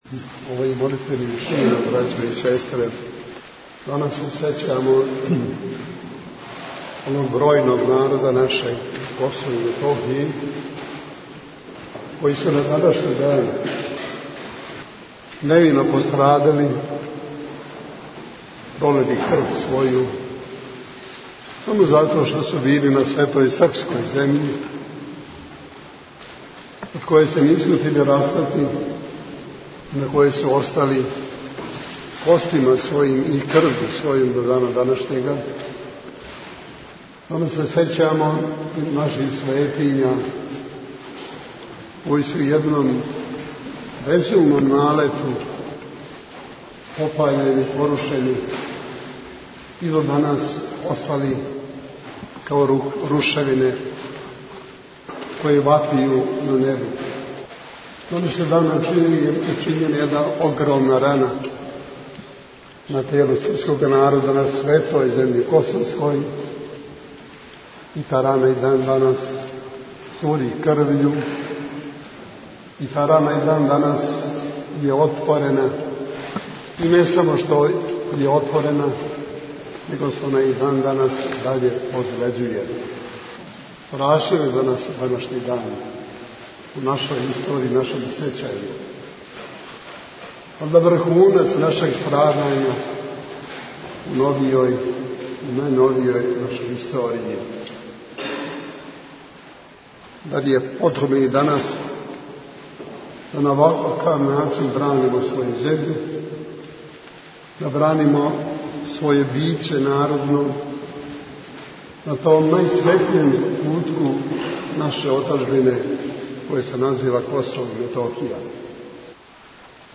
Молитвено сјећање на пострадале започело је Светом Литургијом Пређеосвећених Дарова којом је у београдској Саборној цркви началствовао Његова Светост Патријарх српски Г. Иринеј, уз саслужење Његовог Преосвештенства викарног Епископа хвостанског Г. Атанасија и свештенства Архиепикопије београдско - карловачке.
Tagged: Бесједе
После Литургије Његова Светост Патријарх српски Г. Иринеј служио је парастос пострадалим и дирљивом бесједом подсјетио на дане страдања српског живља и Цркве на Косову и Метохији.